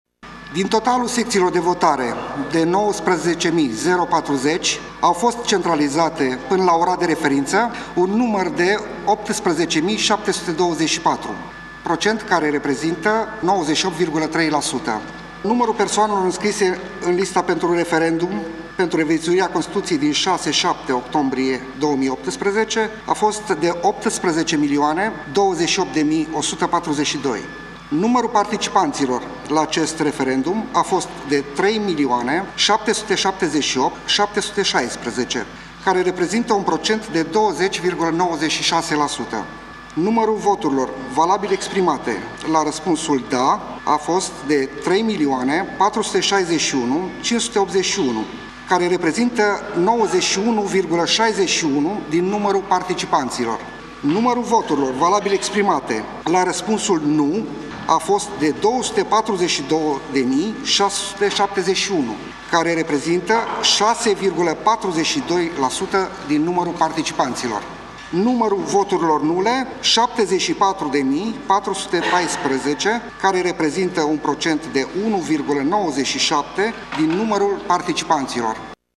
Rezultatele parțiale sunt prezentate de președintele Biroului Electoral Central, Marius Ionescu: